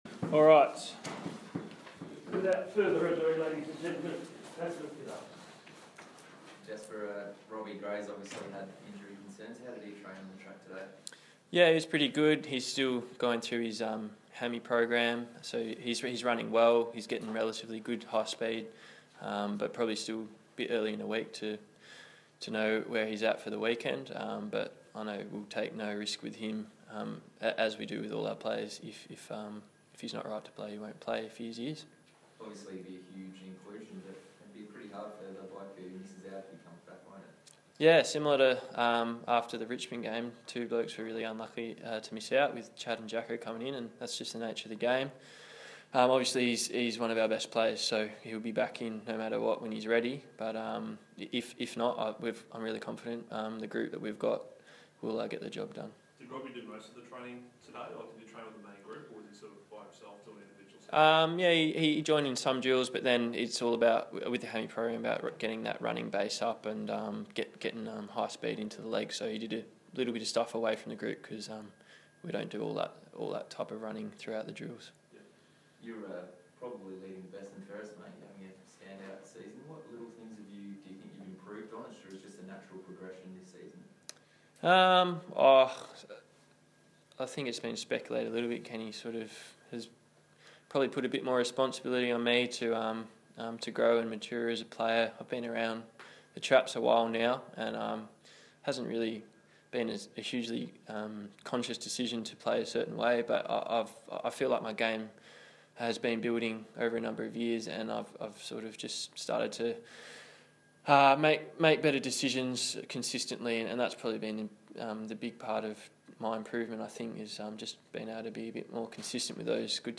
Jasper Pittard Press Conference - Wednesday, 11 May, 2016
Jasper Pittard talks to the media after Port Adelaide's main training session of the week.